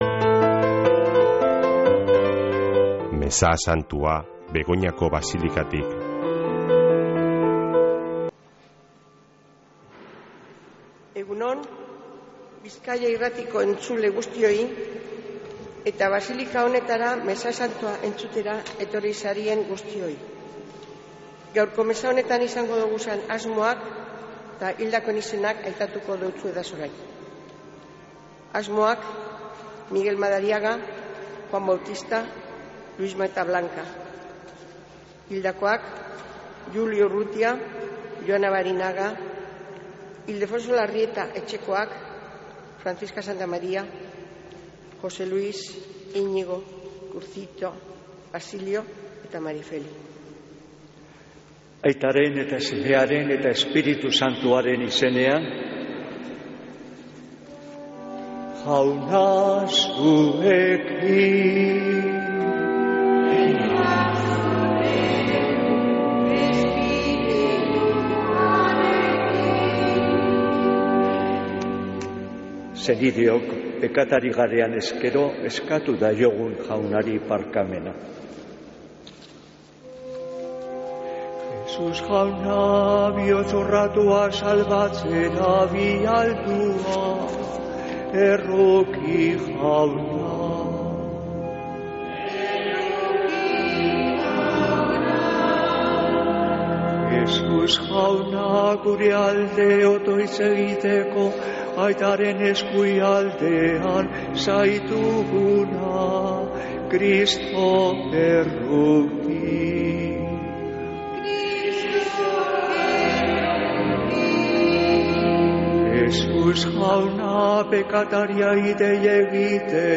Mezea Begoñatik | Bizkaia Irratia